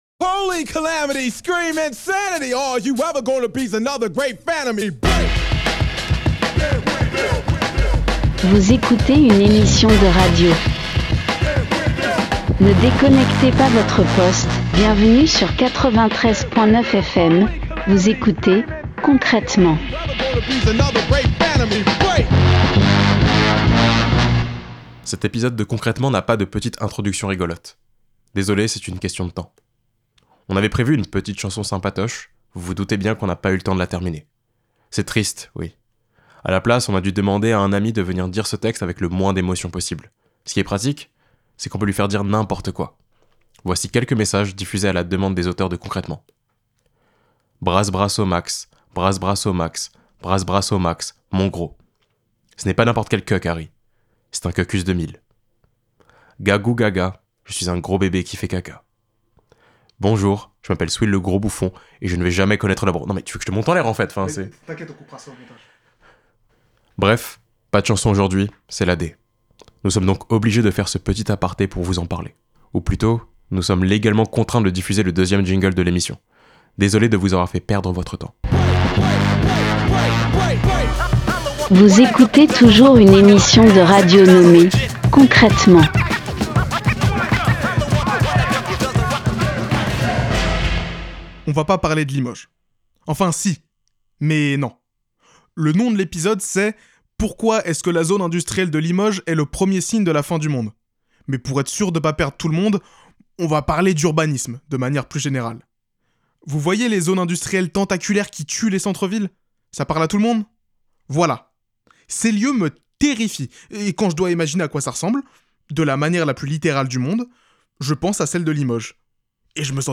Concrètement : Est-ce que la zone industrielle de Limoges est le premier signe de la fin du monde ? Partager Type Création sonore Société vendredi 12 janvier 2024 Lire Pause Télécharger L'urbanisme est un art, et l'art est mort: Limoges en est la preuve.